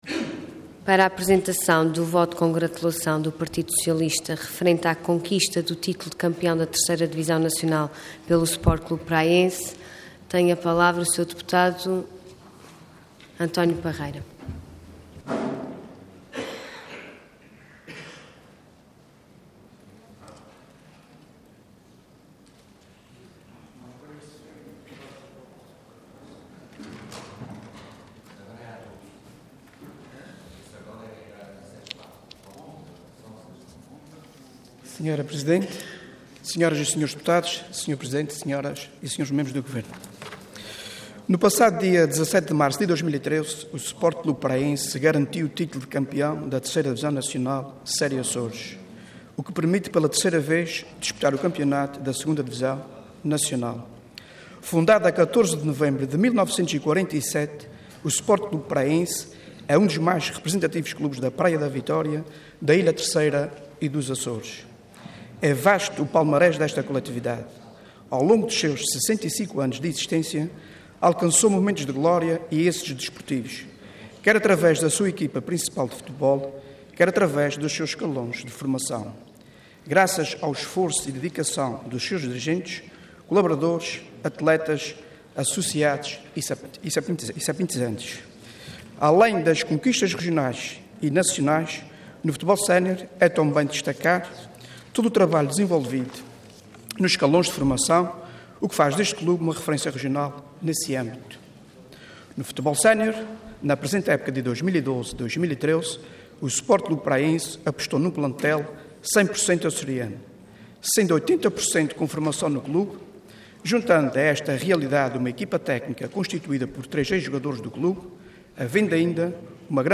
Intervenção Voto de Congratulação Orador António Parreira Cargo Deputado Entidade PS